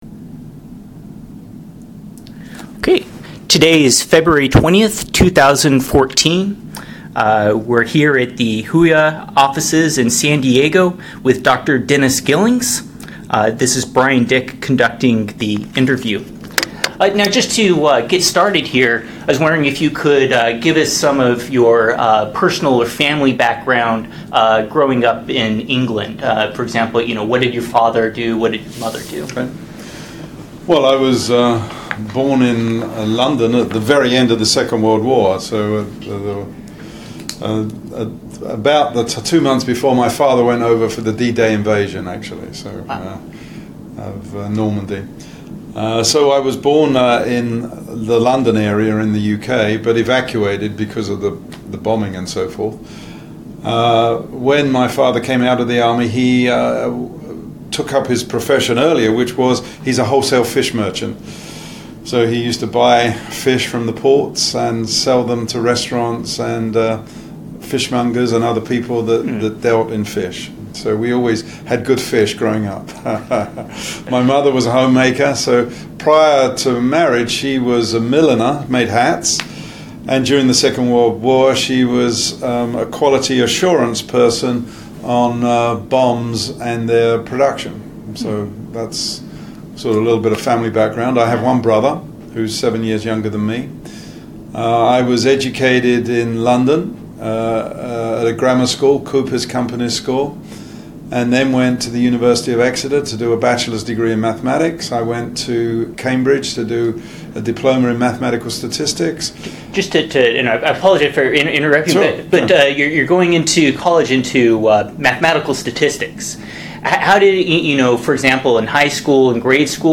Research Interview with Dennis Gillings
Oral histories